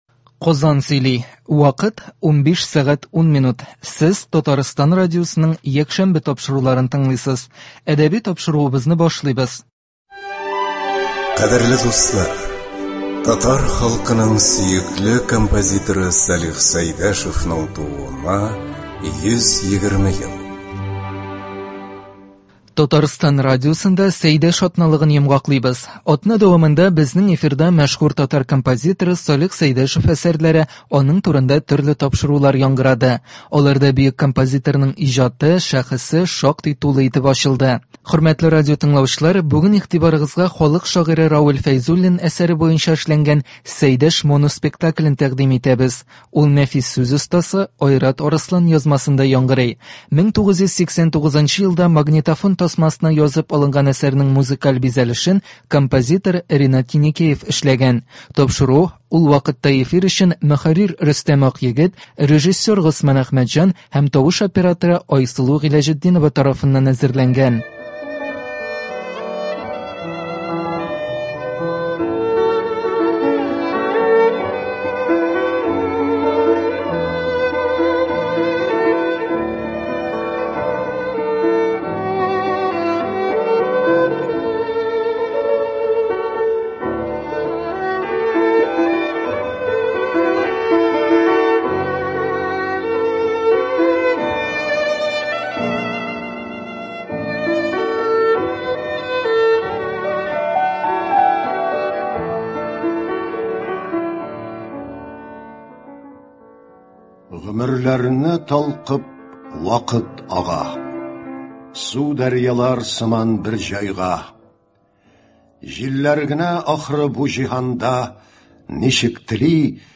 “Сәйдәш”. Моноспектакль.